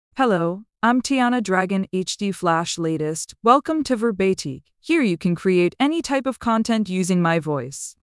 FemaleEnglish (United States)
Tiana Dragon HDFlash LatestFemale English AI voice
Tiana Dragon HDFlash Latest is a female AI voice for English (United States).
Voice sample
Listen to Tiana Dragon HDFlash Latest's female English voice.
Female